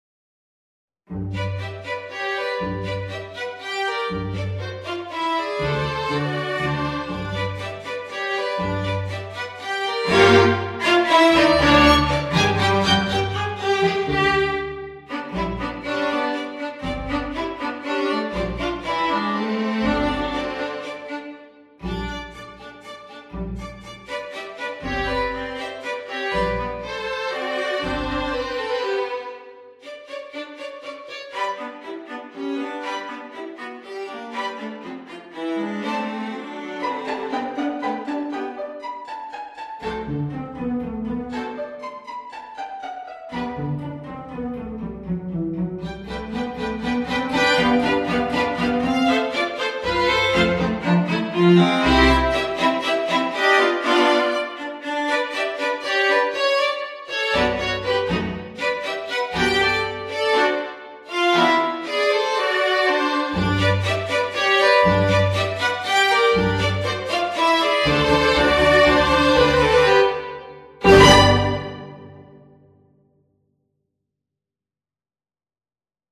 String Quintet - Joke
Well, here is a tiny string quintet I wrote recently.